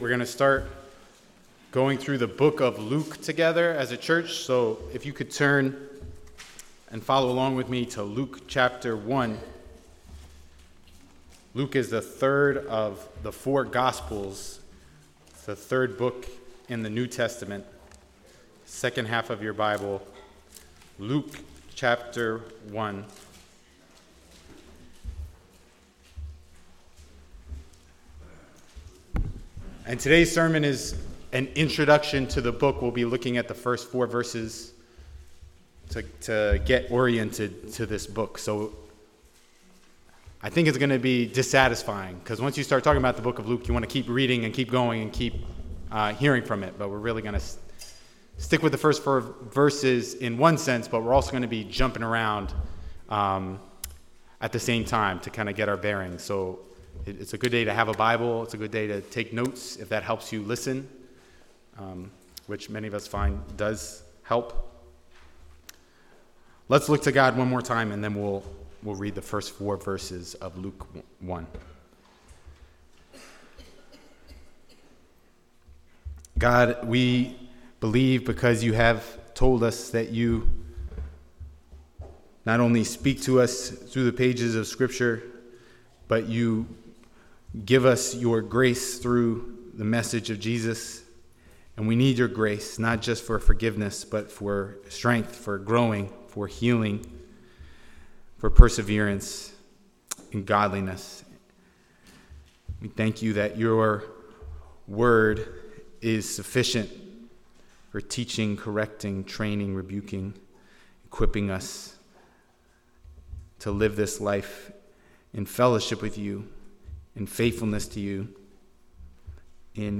Passage: Luke 1:1-4 Service Type: Sunday Morning